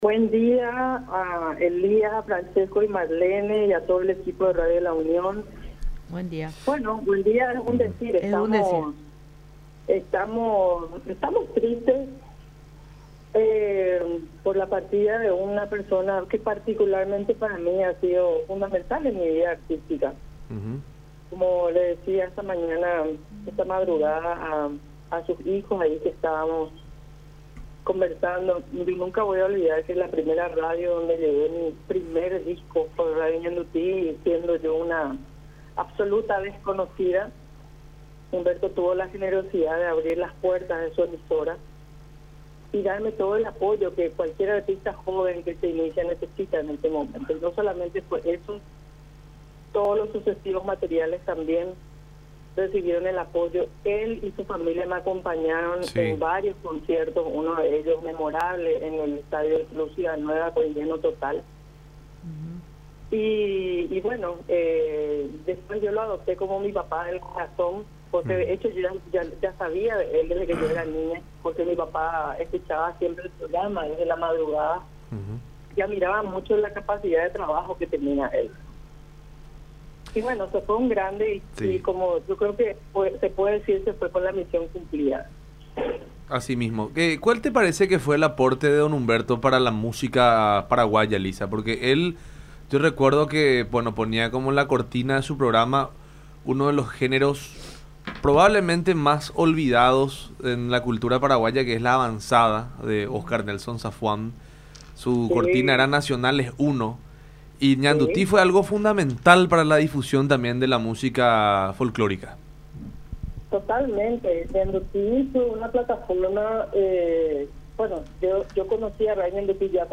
Siendo yo una absoluta desconocida, él (Humberto) tuvo la generosidad de abrirme las puertas de su emisora y darme todo el apoyo que cualquier artista joven necesita en ese momento”, mencionó Bogado en contacto con La Unión Hace La Fuerza por Unión TV.